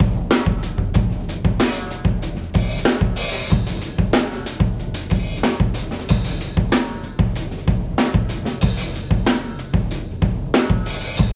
Room Mic Comparison
Late night tracking session for upcoming compilation, Set up three different stereo room fill mics.
They all sound great – the PZM for a rounder old-skool feel, the 414 or MD1 for a bit more punch!